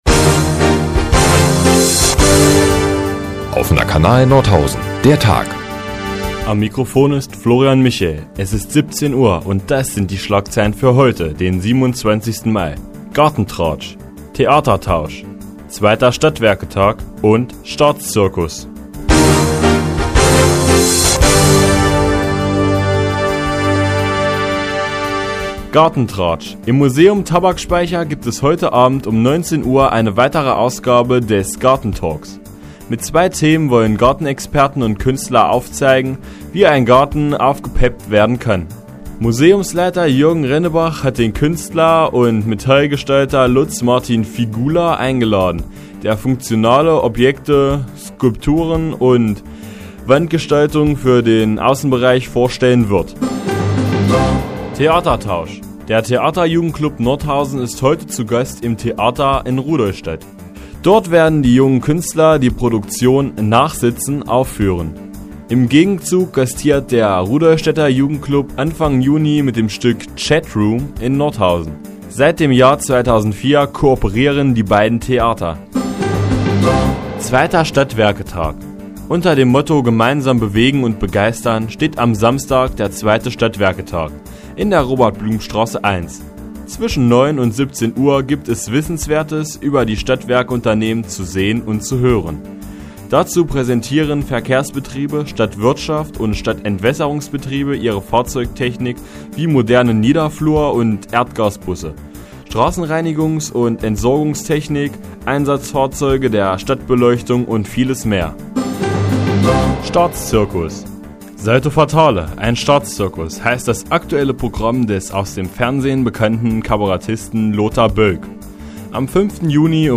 Die tägliche Nachrichtensendung des OKN ist nun auch in der nnz zu hören. Heute geht es um unseren Nordhäuser Theaterjugendklub in Rudolstadt und den 2. Stadtwerketag unter dem Motto ,,Gemeinsam bewegen und begeistern".